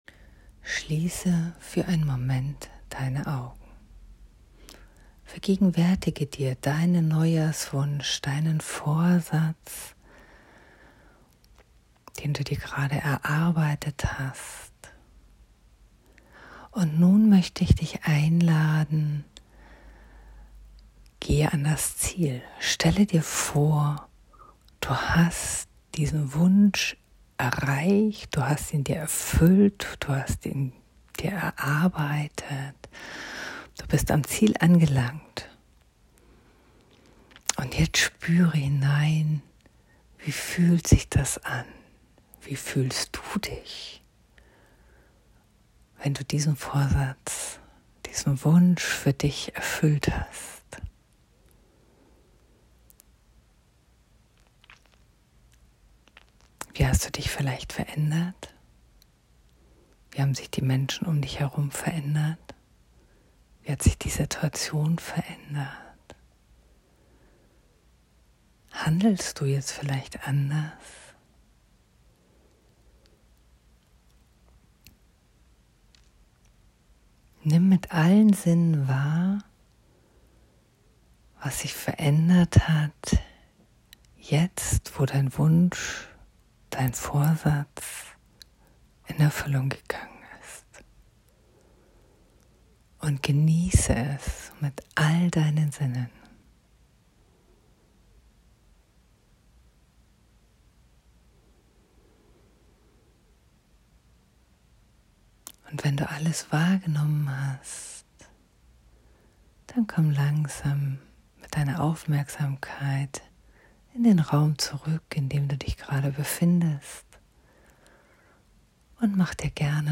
Mini-Meditation (2 min)
Mini-Meditation.m4a